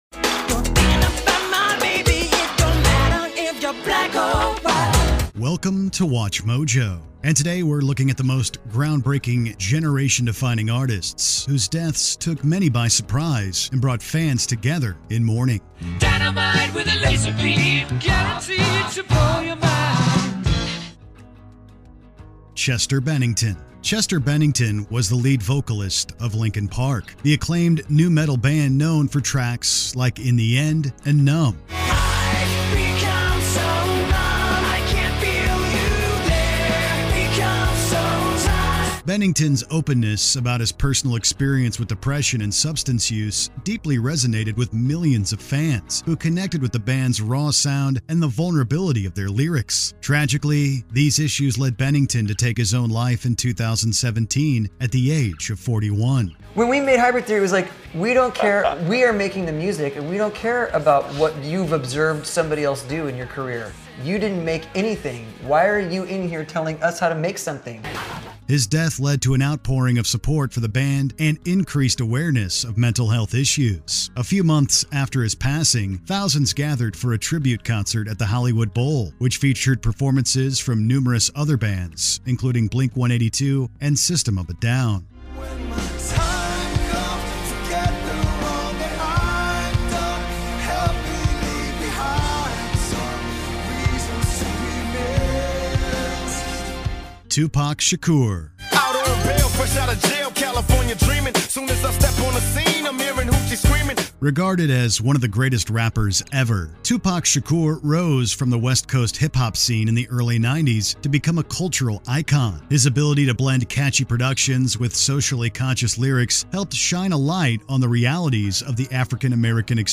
English - USA and Canada
Middle Aged